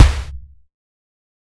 ゲーム制作用
【アタック音】パンチ